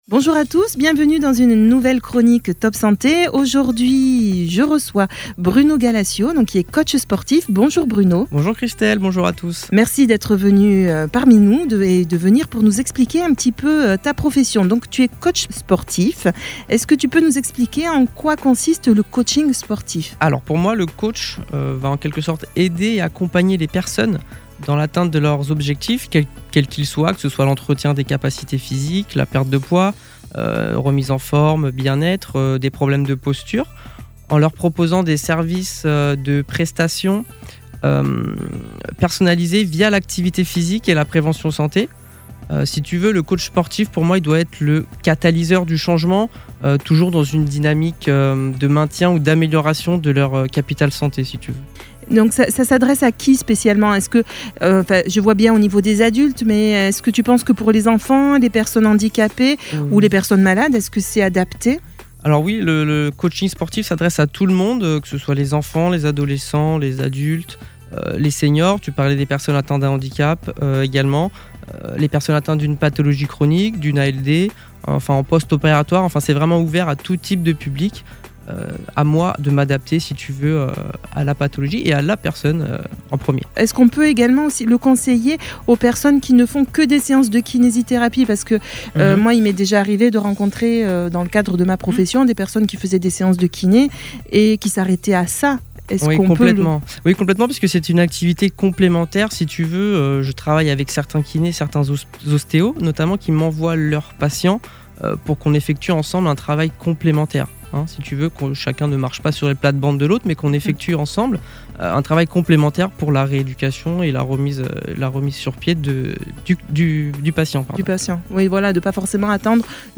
Votre chronique Top Santé à retrouver chaque semaine sur Radio Top SIde avec notre partenaire Soin du visage anti-rides et anti-âge qop